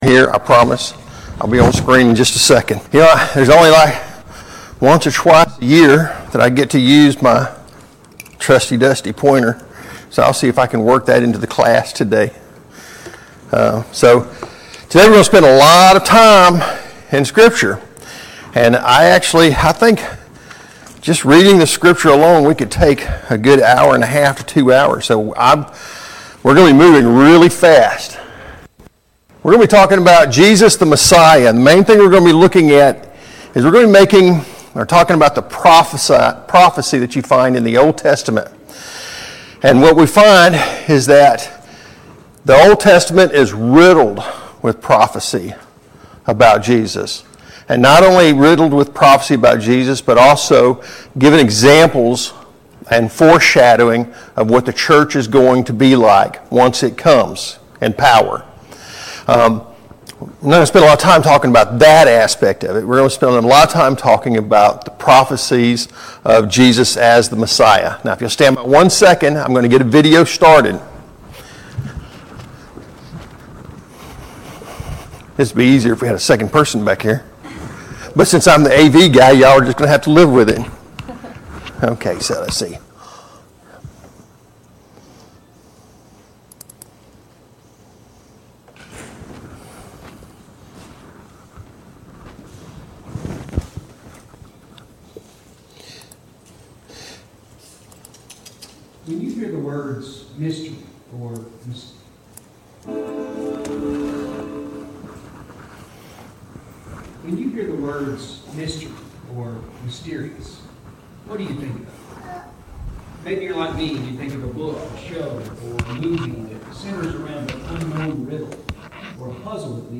1. Jesus, the Messiah – 2025 VBS Adult Lesson